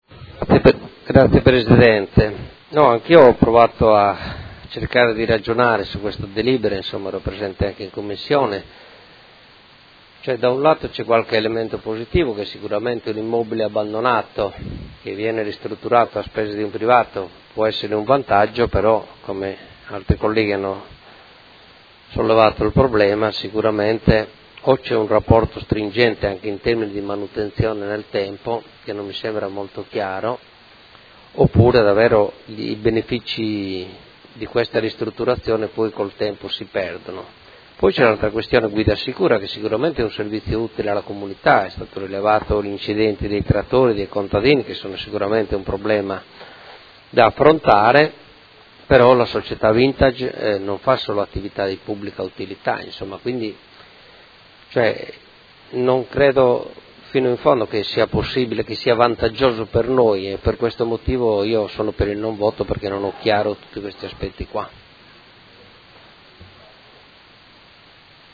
Seduta del 10/12/2015.
Dichiarazioni di voto